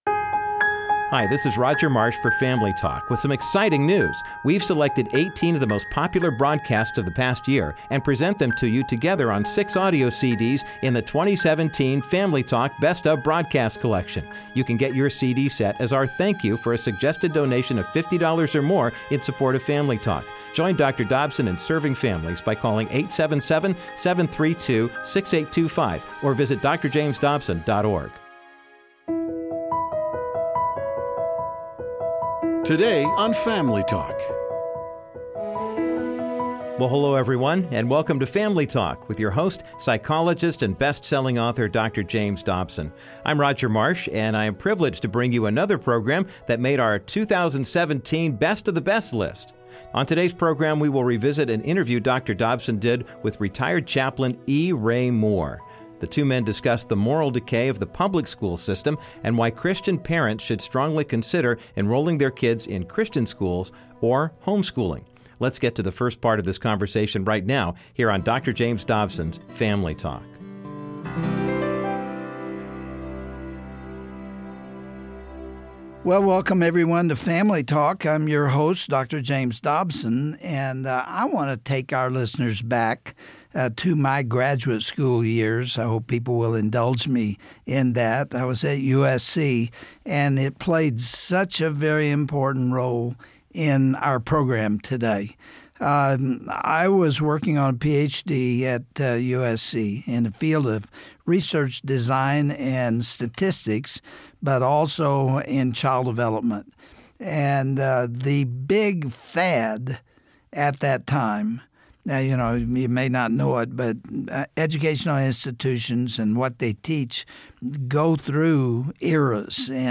Today Family Talk is excited to continue the Best of 2017 Broadcasts all this month.